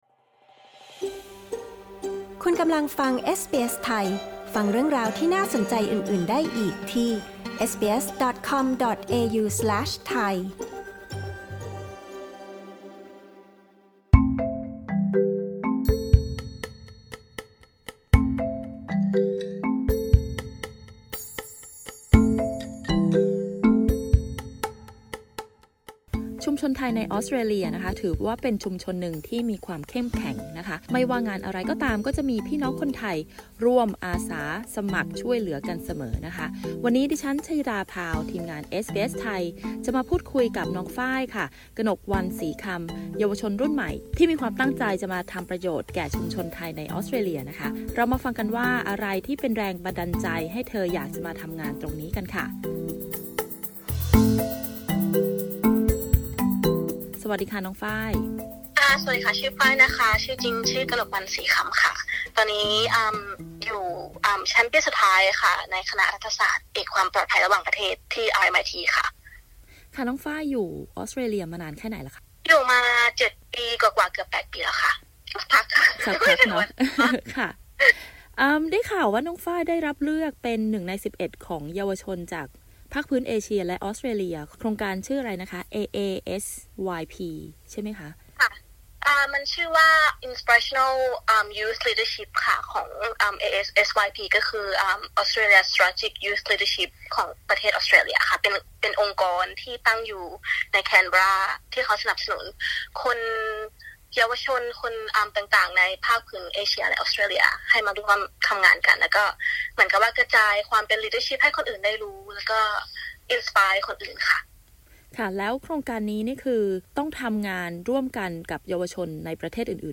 ประเด็นสำคัญในการสัมภาษณ์ การทำงานเพื่อชุมชนไทยมีประโยชน์อย่างไร